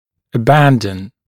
[ə’bændən][э’бэндэн]прекращать делать что-л.